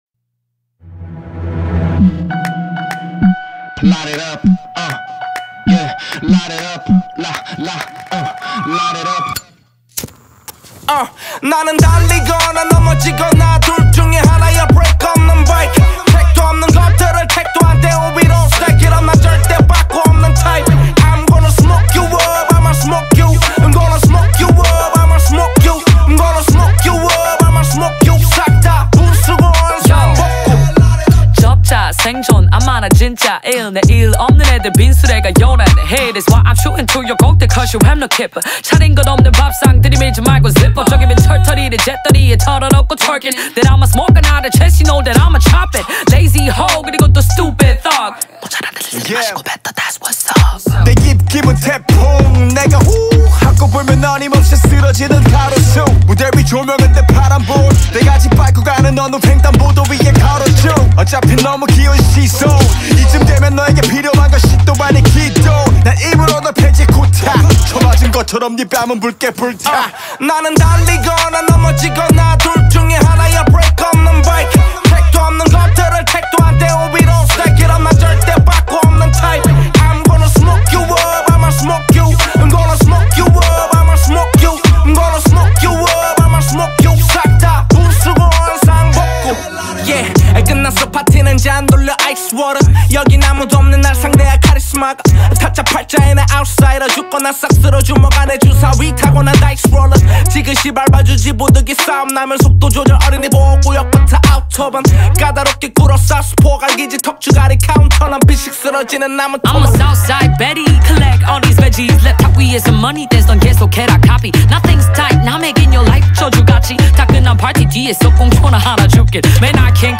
одна из самых влиятельных хип-хоп групп Южной Кореи